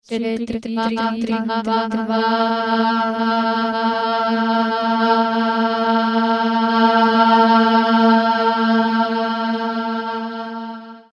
Ex4 Grain  123Ex4.mp3